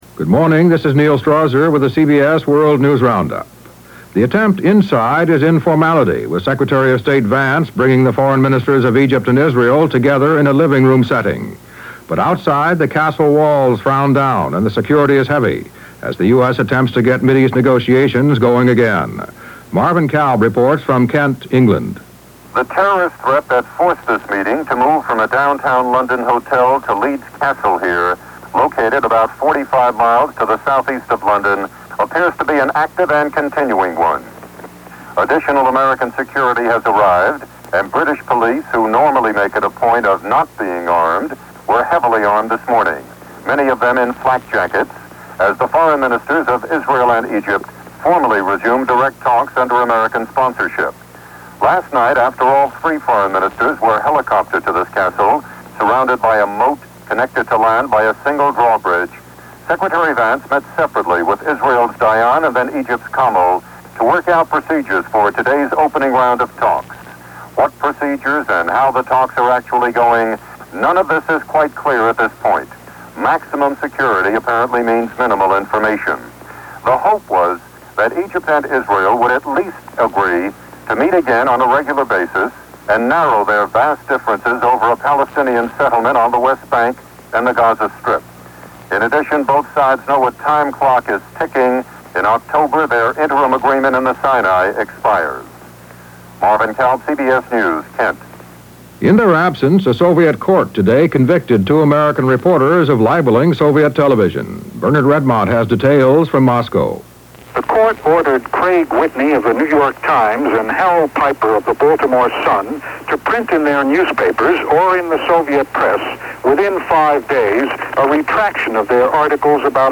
And while the Middle-East summit was unfolding, that’s just a small slice of what happened on this July 17, 1978 as reported on The CBS World News Roundup.